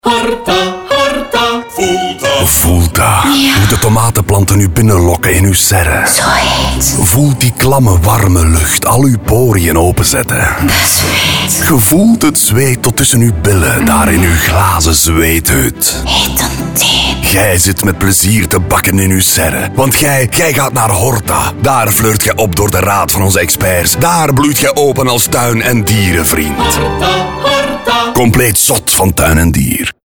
Vier radiospots die opnieuw de zintuigen prikkelen. Eéntje van 30 seconden over een serre waarin serrieus wat wordt afgezweet.